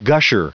Prononciation du mot gusher en anglais (fichier audio)
Prononciation du mot : gusher